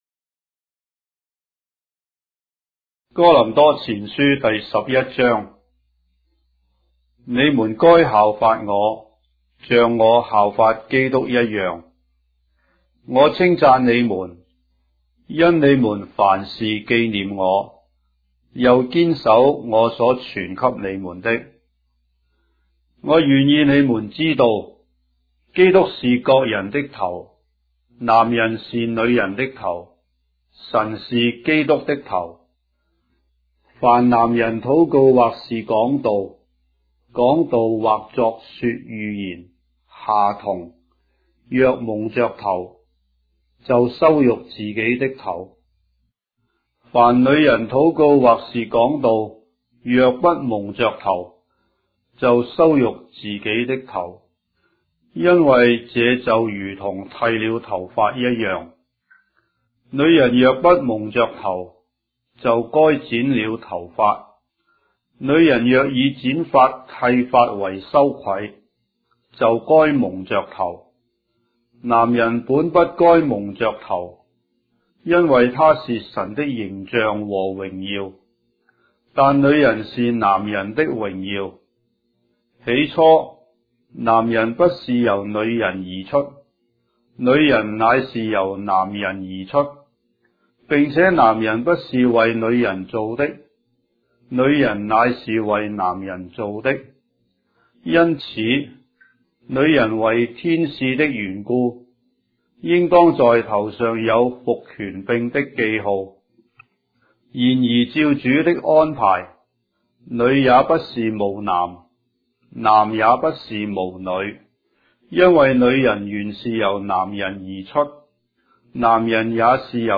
章的聖經在中國的語言，音頻旁白- 1 Corinthians, chapter 11 of the Holy Bible in Traditional Chinese